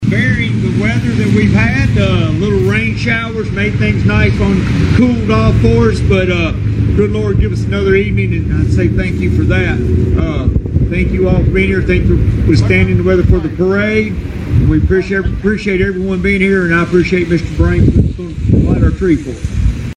Hopkinsville Mayor J. R. Knight said it was a great night to celebrate the Christmas season.